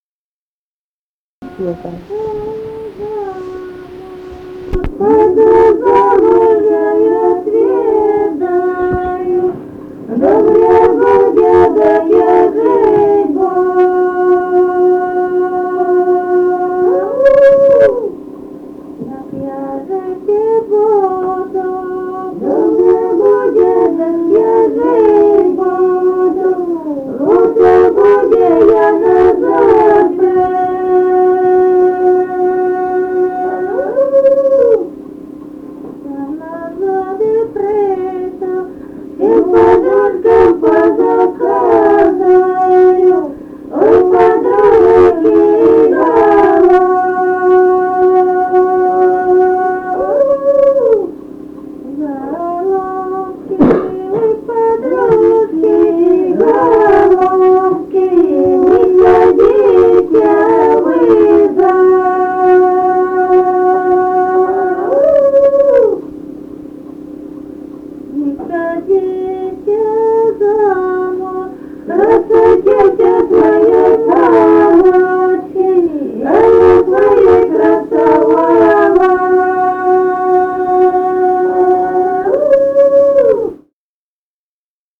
Музыкальный фольклор Климовского района 048. «Пойду замуж, я й отведаю» (свадебная).
Записали участники экспедиции